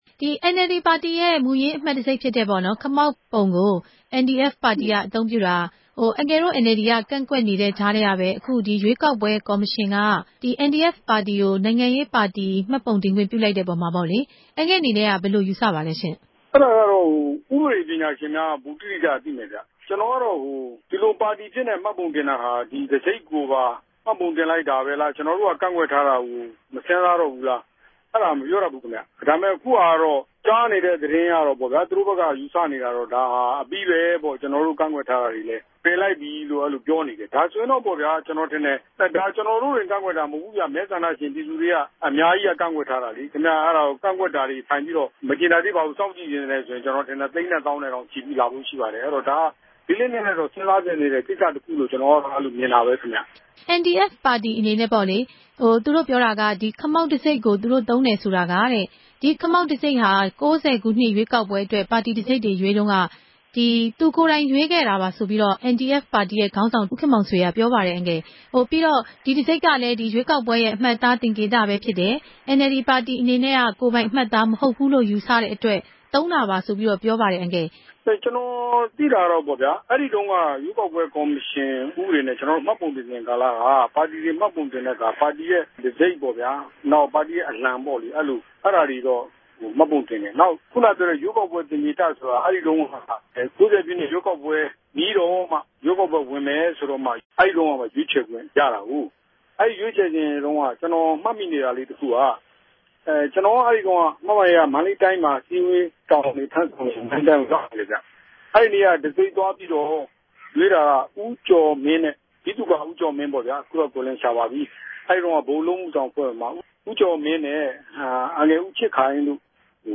ဆက်သြယ် မေးူမန်းတင်ူပထားပၝတယ်။